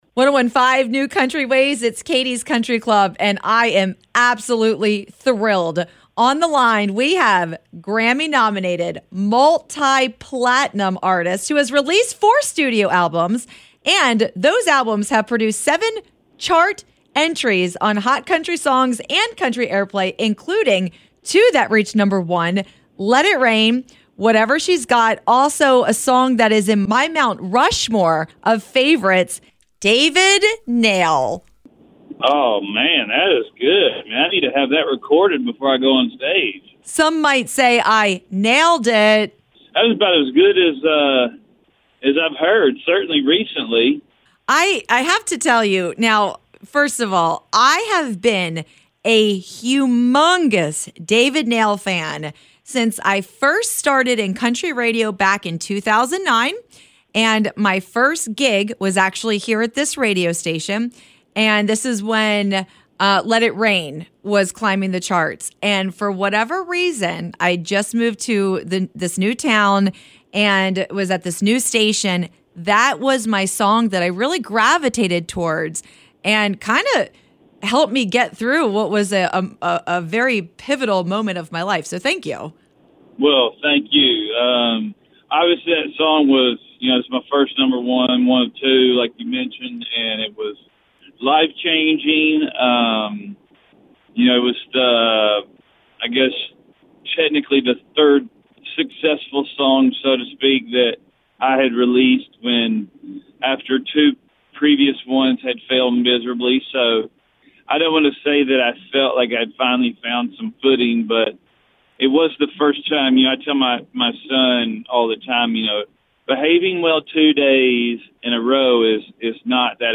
David Nail Interview
david-nail-interview.mp3